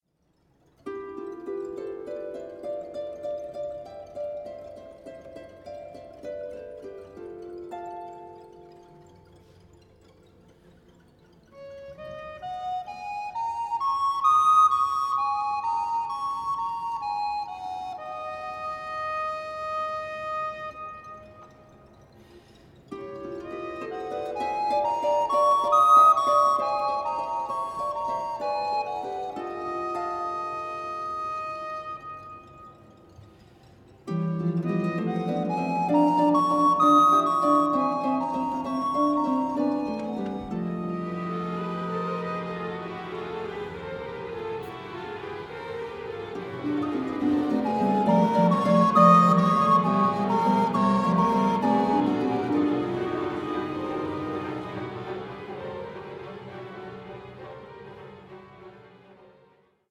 Concerto for Harp, Recorder and String Orchestra (2004/2012)